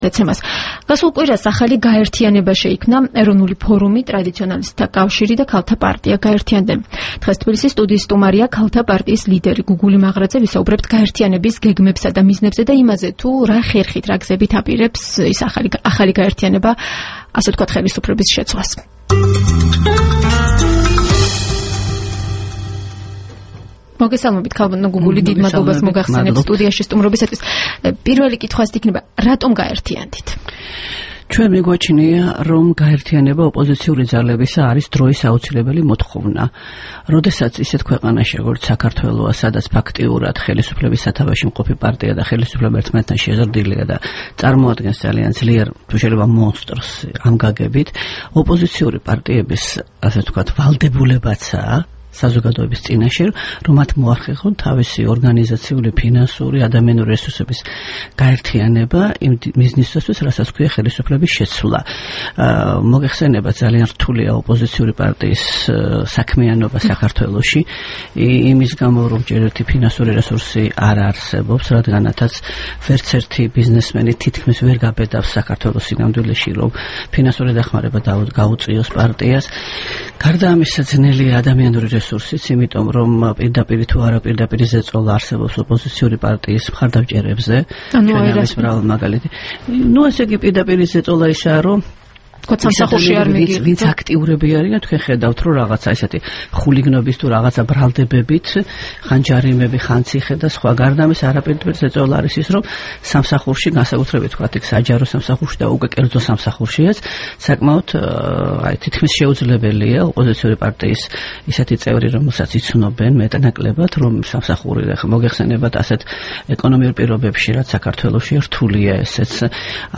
საუბარი გუგული მაღრაძესთან